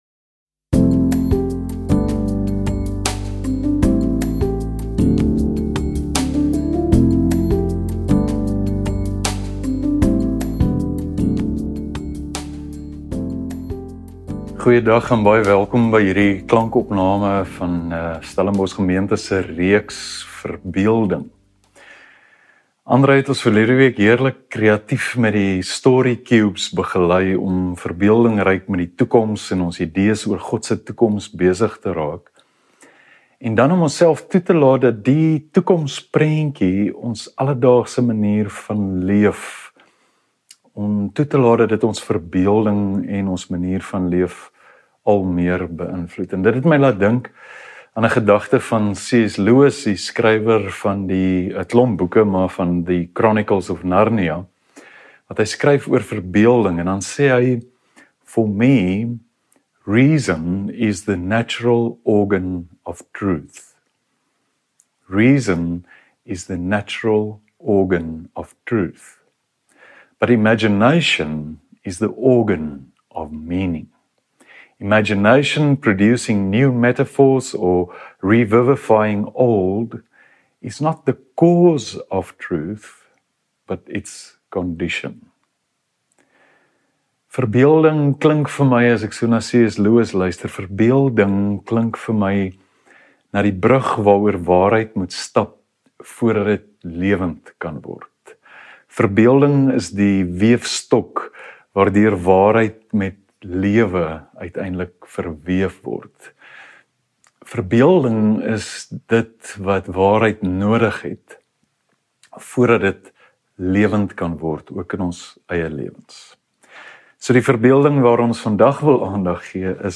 1 Preek | Sondag, 9 Maart 37:44 Play Pause 4d ago 37:44 Play Pause बाद में चलाएं बाद में चलाएं सूचियाँ पसंद पसंद 37:44 Die verbeel-ding waaraan ons vandag wil aandag gee is KINDWEES…en dit klink eenvoudig en ek glo dit is, maar dit is soos meeste eenvoudige dinge nogal moeilik vir grootmense.